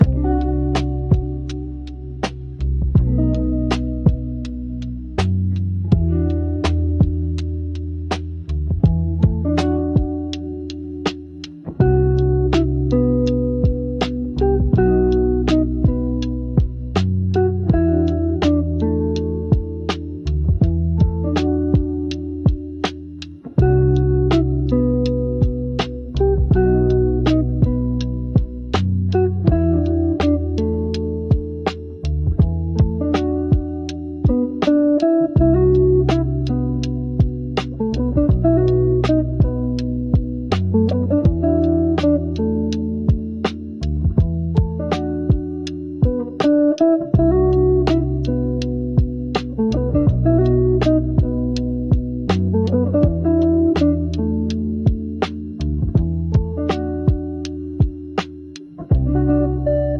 Royalty-free chillhop for cozy nights & content creators.